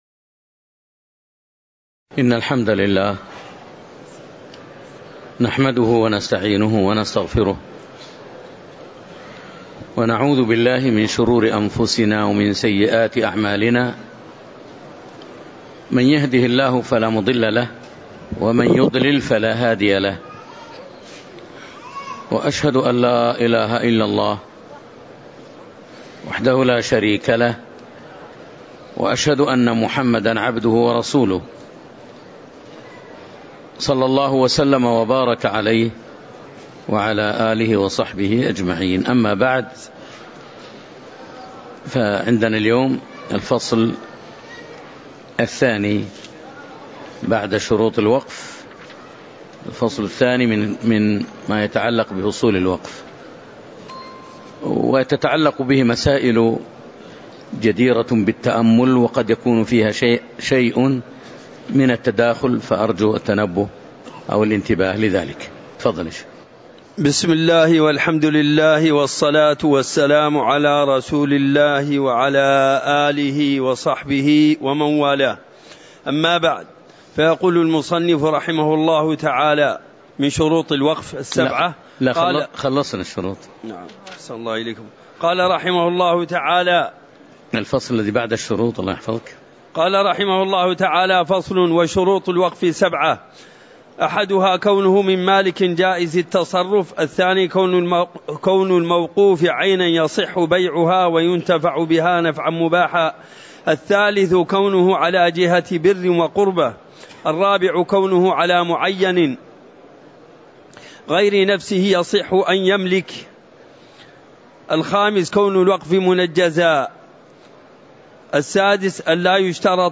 تاريخ النشر ١ ربيع الأول ١٤٤٤ هـ المكان: المسجد النبوي الشيخ